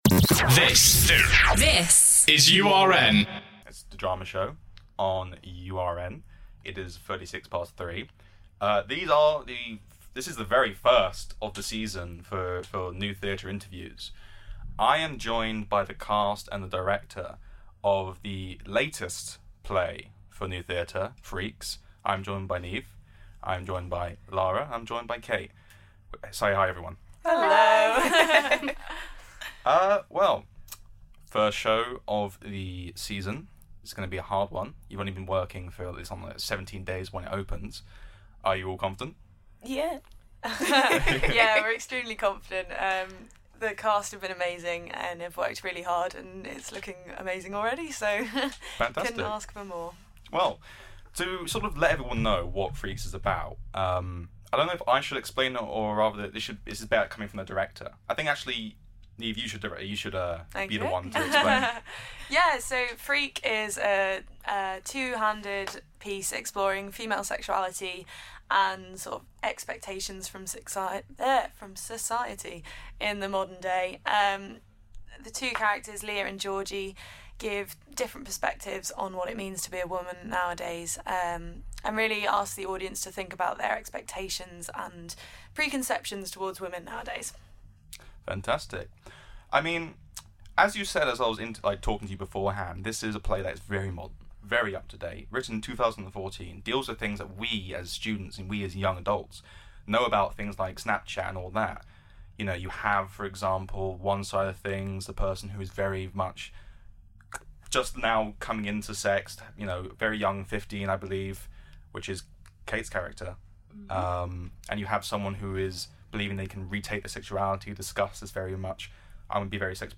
URN interviews Nottingham New Theatre's 'Freaks' 30/10/16